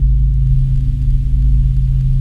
ATMOPAD19.wav